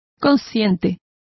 Complete with pronunciation of the translation of mindful.